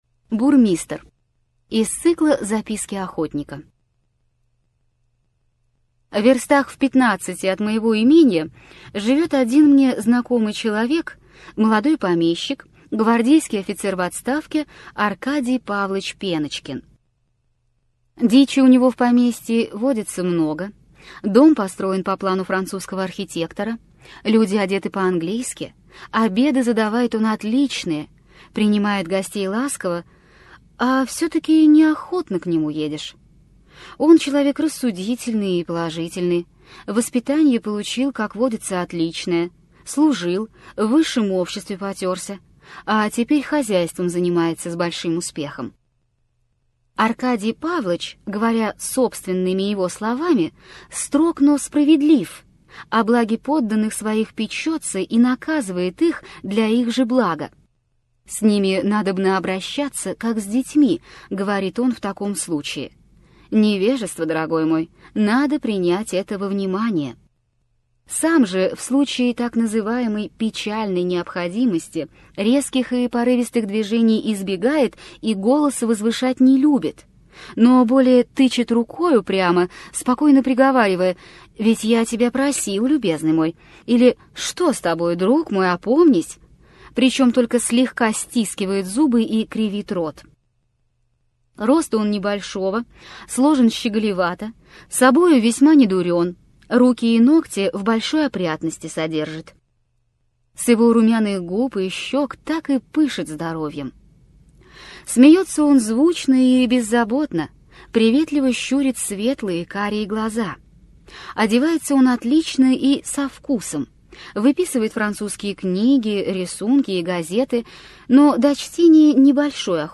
Аудиокнига Из записок охотника: Бирюк. Бежин луг. Бурмистр | Библиотека аудиокниг